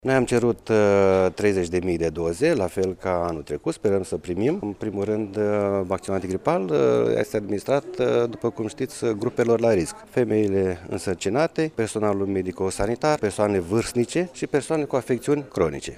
Directorul Direcţiei de Sănătate Publică Iaşi, Liviu Stafie a declarat că s-au solicitat 30 de mii de doze de la nivel central pentru această campanie: